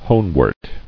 [hone·wort]